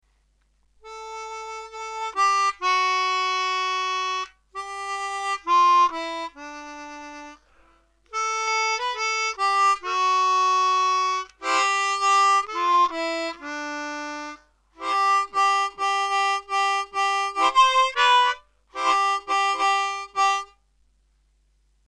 3D..3D..3B..2D#   (with hand vibrato)
3B   2D   2B   1D    (with gentle hand vibrato)